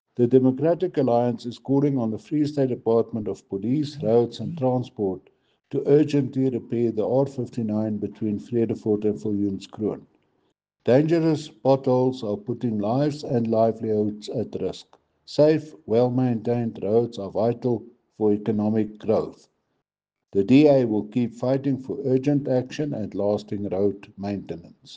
English and Afrikaans soundbites by Cllr. Brendan Olivier and